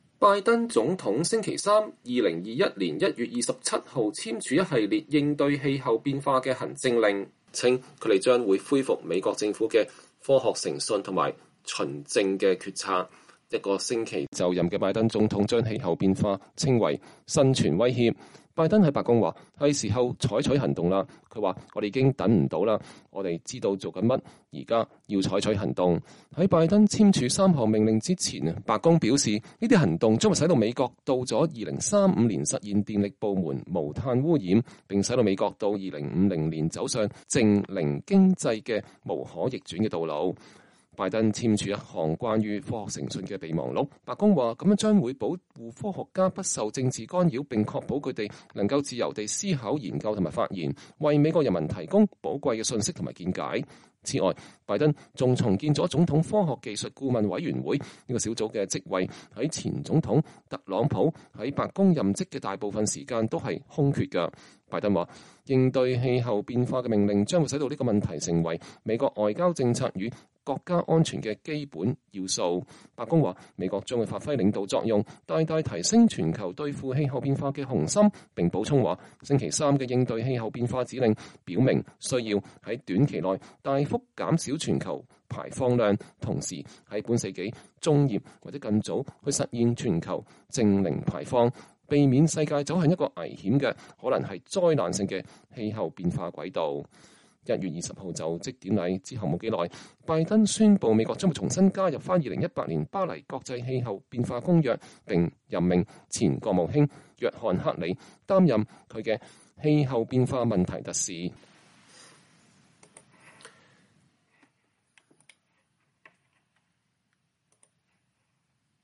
拜登總統2021年1月27日在白宮就氣候變化和綠色工作發表講話。